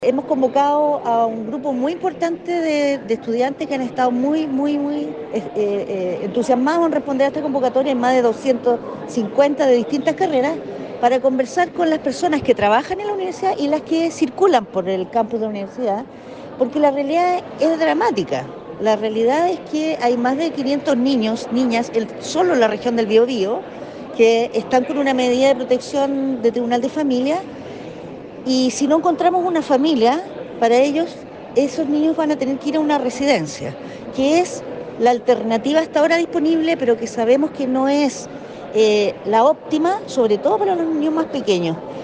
La actividad, efectuado en el Auditorio de la Facultad de Ciencias Jurídicas y Sociales durante la mañana de este jueves, se enmarca en una nueva conmemoración de la Declaración de los Derechos del Niño, proclamada por las Naciones Unidas en 1959, motivo por el que cada 20 de noviembre se celebra el Día Internacional de la Infancia.